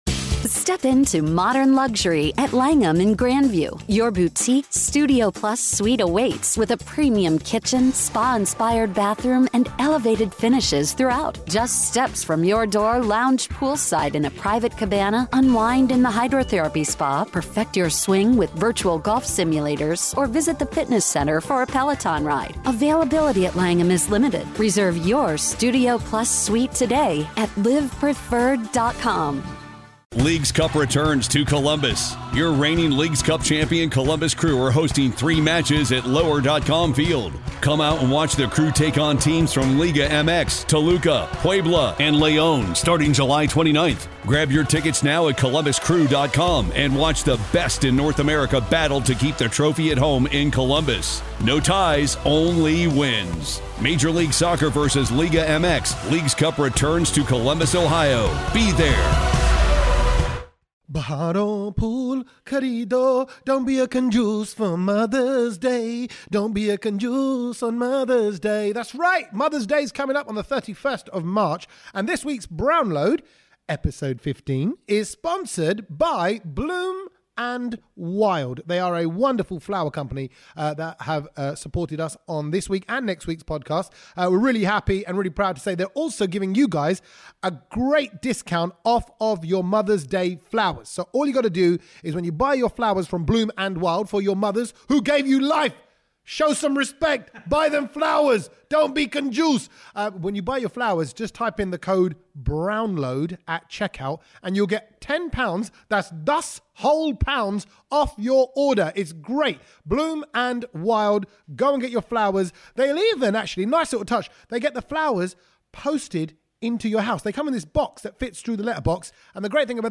Punjabi Rockstar, Juggy D joins the lads to talk about his recent new arrival, his new music and gives his best shot at Podioke.